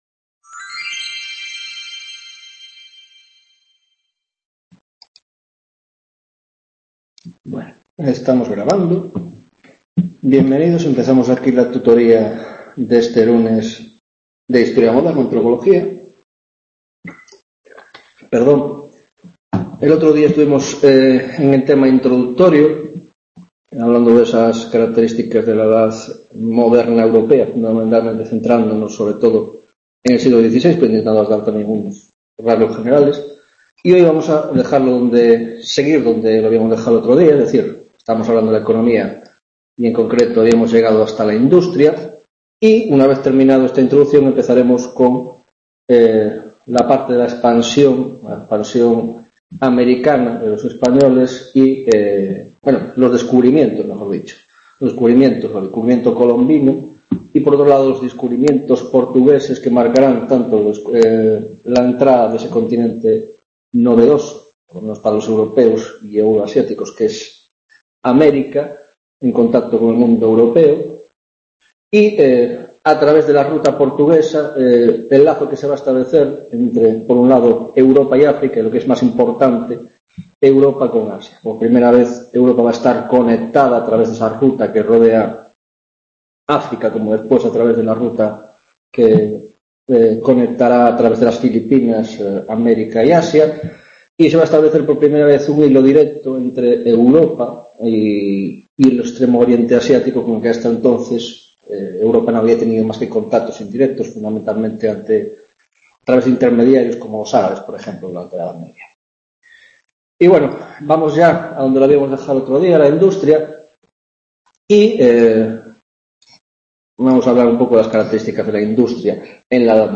2ª tutoría de Historia Moderna